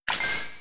egg_hatch.wav